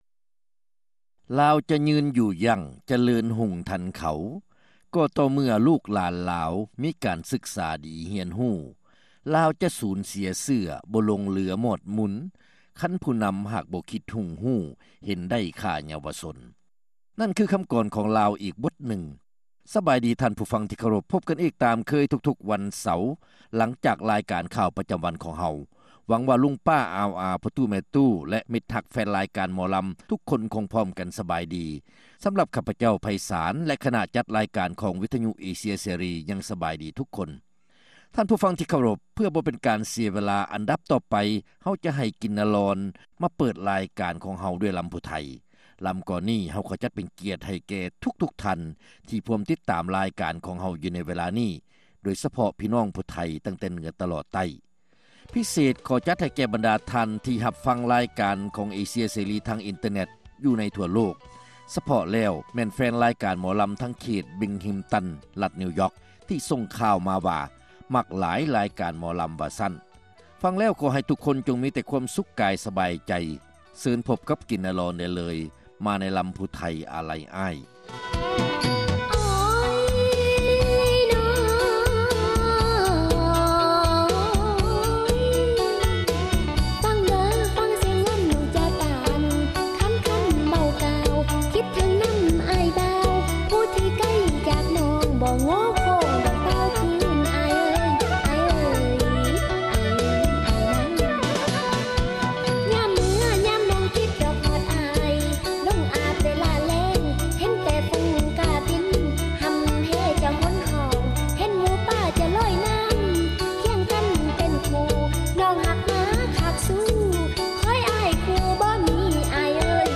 ຣາຍການໜໍລຳ ປະຈຳສັປະດາ ວັນທີ 26 ເດືອນ ພືສະພາ ປີ 2006